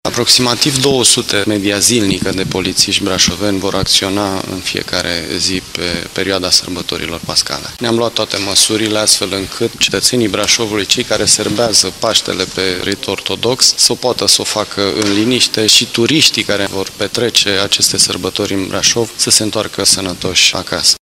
Oamenii legii vor veghea în aceste zile de sărbătoare la buna desfășurare a activităților fiind prezenți atât pe străzile Brașovului cât și în Noaptea de Înviere la bisericile din oraș, arată chestorul Valentin Flucuș, șeful Inspectoratului de Poliție al Județului Brașov: